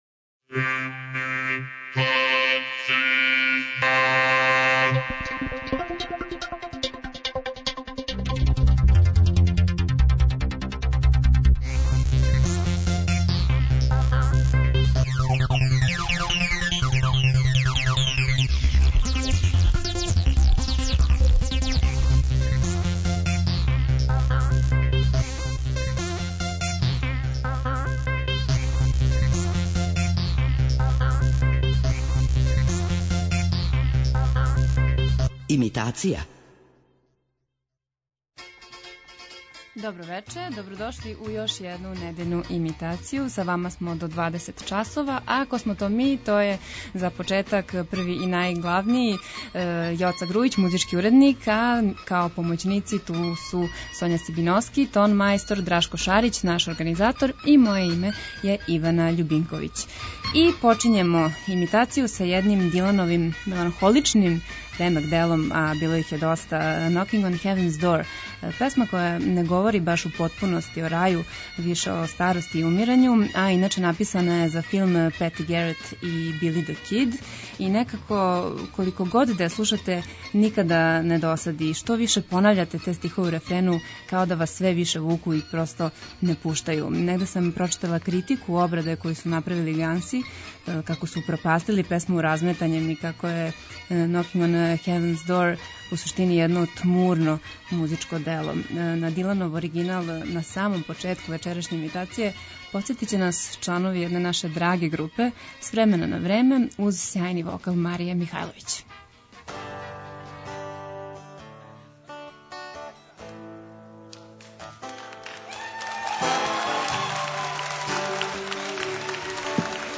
преузми : 14.12 MB Имитација Autor: Београд 202 Имитација је емисија у којој се емитују обраде познатих хитова домаће и иностране музике.